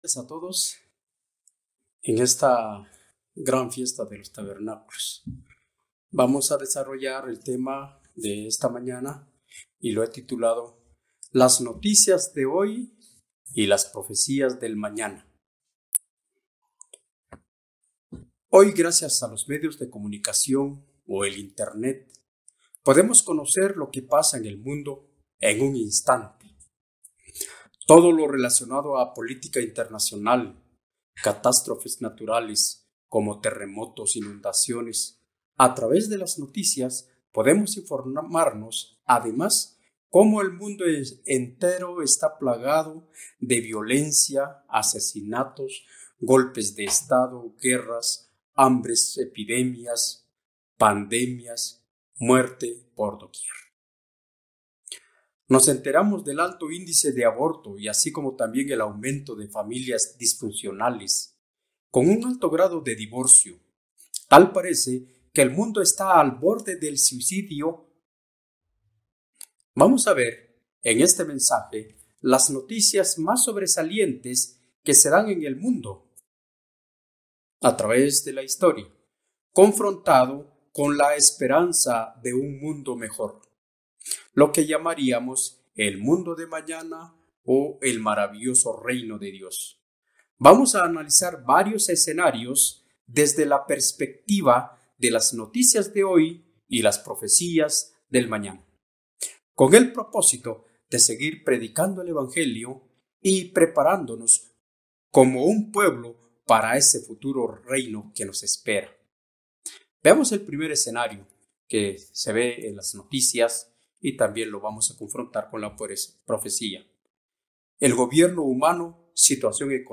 La cada vez mayor cercanía a los tiempos del fin, nos insta a mantenernos vigilantes de la profecía bíblica... y de los titulares cotidianos. Mensaje entregado el 22 de septiembre de 2021.